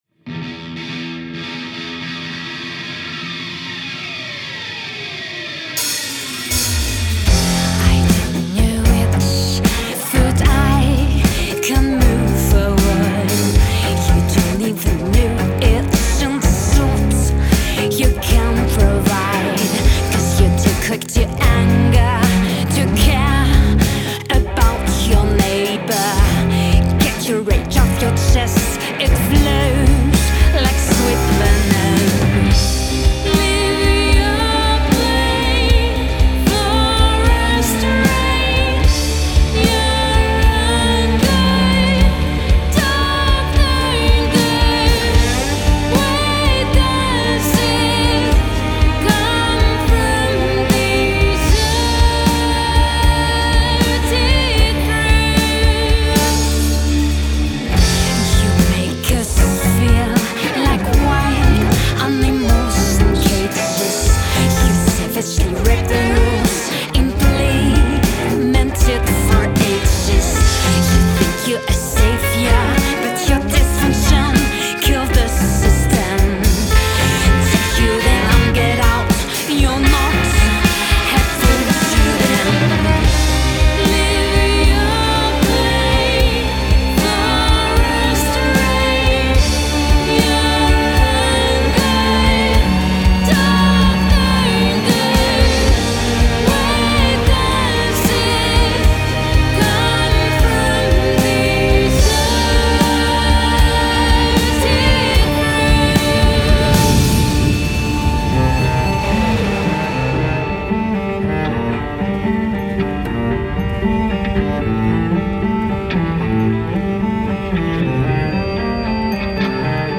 Rock Poétique